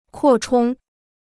扩充 (kuò chōng): 拡充; 拡大.